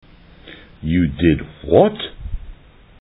A conversation between a student and a professor.
Taking the context of the conversation and the way the professor spoke, you can understand that he is angry with the student.